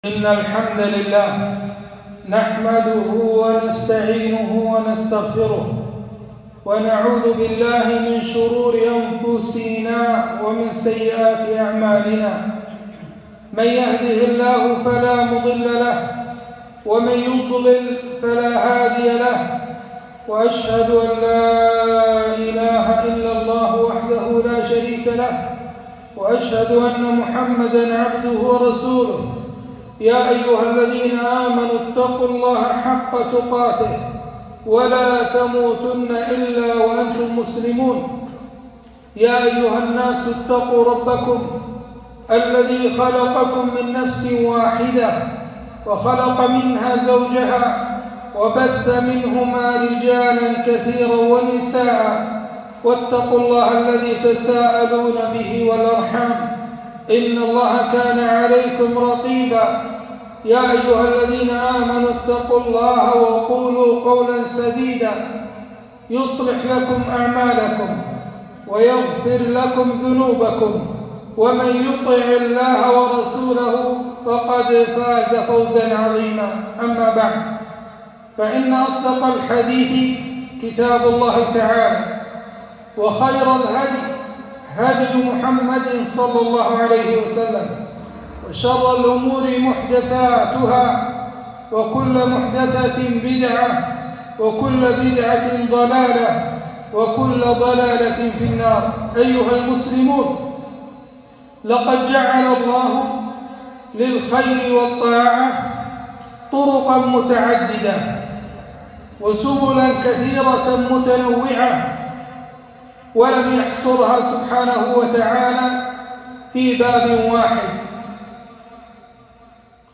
خطبة - فضل إماطة الأذى عن الطريق